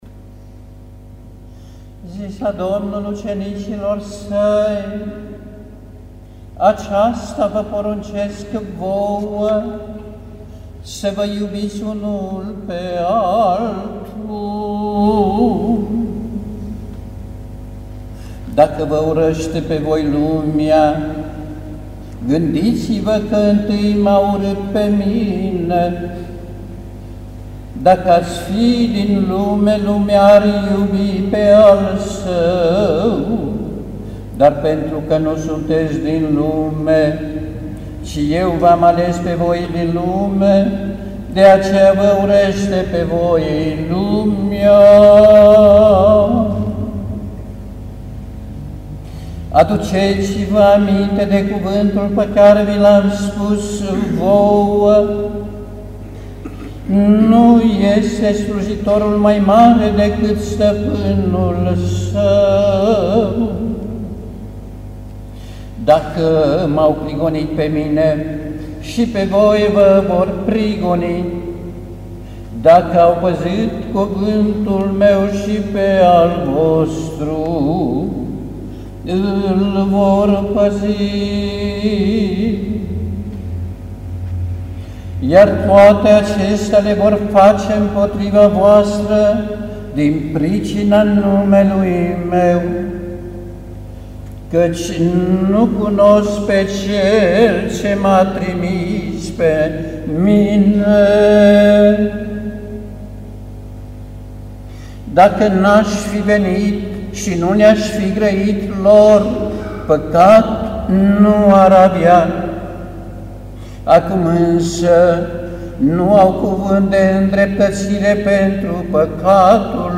Incepând cu orele 16, Preafericirea Sa Lucian, arhiepiscopul Major al BRU împreună cu ceilalţi episcopi greco-catolici şi un sobor de preoţi au săvârţit slujba Te Deum Laudamus, a cărei pericopă evanghelică din Evanghelia după Ioan (15,17-16,2), încărcată de semnificaţii pentru Biserica Greco-Catolică a fost citită de Preafericirea Sa: „Aceasta vă poruncesc vouă: Să vă iubiţi unul pe altul! Dacă vă urăşte pe voi lumea, gândiţi-vă că întâi M-a urât pe Mine.(…) Iar toate acestea le vor face voua din pricina numelui Meu, căci nu cunosc pe Cel ce M-a trimis pe Mine…”
Ascultă Evanghelia-Te-Deum-21.10.2008